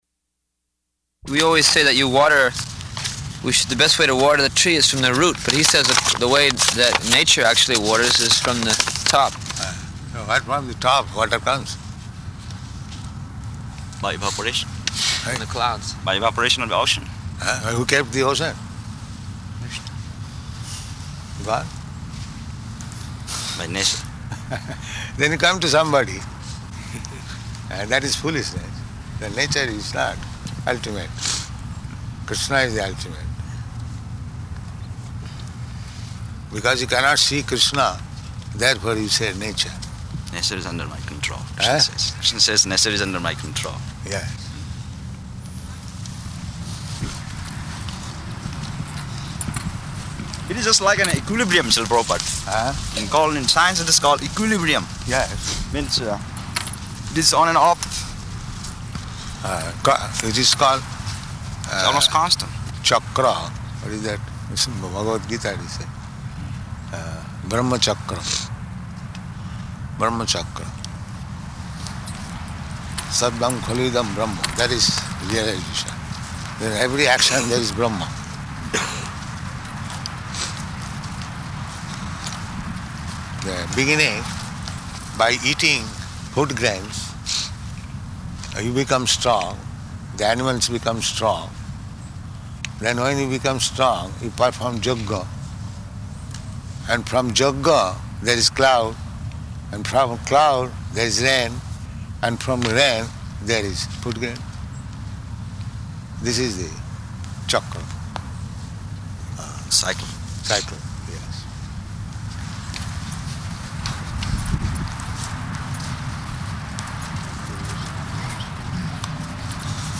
Type: Walk
Location: Los Angeles